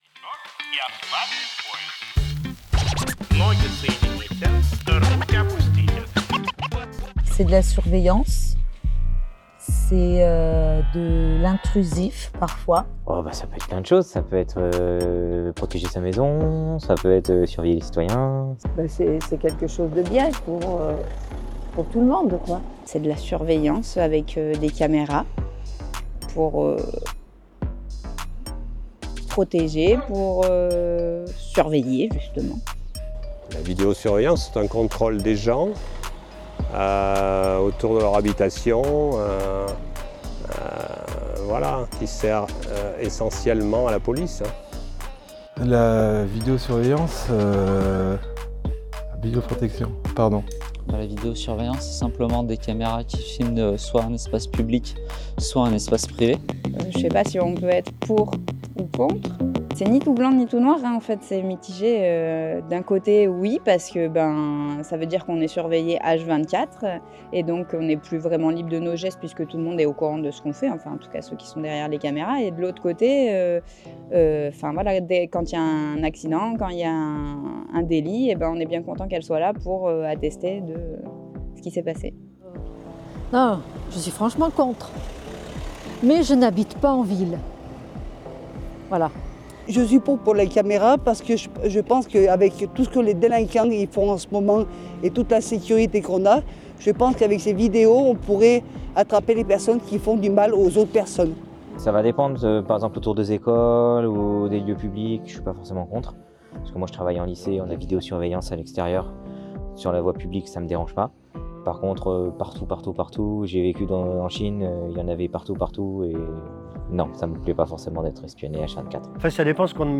Vendredi 7 février s’est tenue la 3ème édition des soirées de débat organisées par le Centre Social La Paz. Les sujets qui grattent sont l’occasion de réunir citoyens et citoyennes, habitant dans le Royans et le Vercors pour débattre de sujets polémiques.